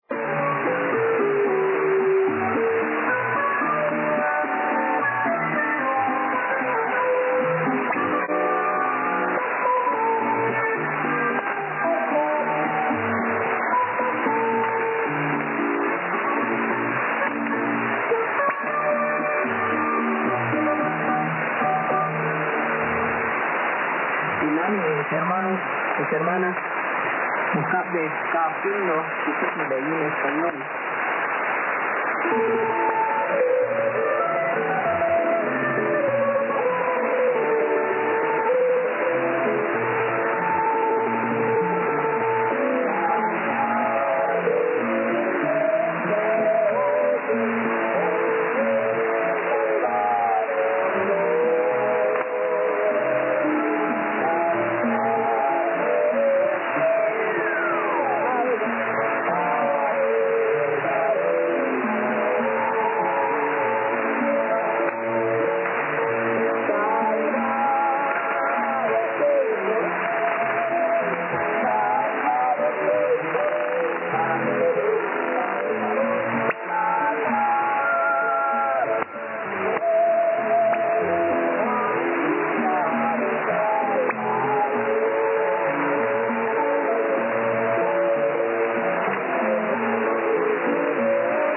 guatemalteca Coatan, su 4780 kHz, che comincia ad arrivare fin dalle 22.30z, orario abbastanza inusuale. Sentite che bel programma musicale da questa stazione religiosa (l'unica tipologia autorizzata sulle corte in Guatemale, credo).